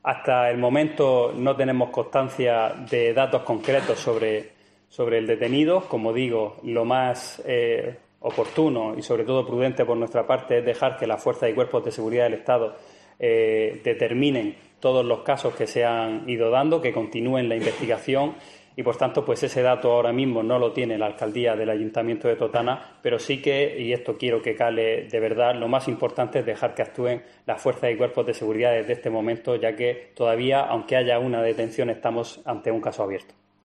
Pedro José Sánchez, alcalde de Totana